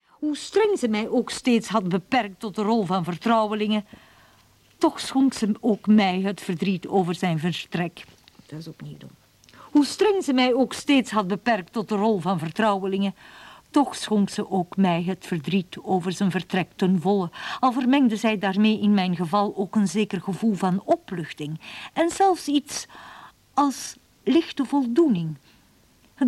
Hoorspel anders: Lotte in Weimar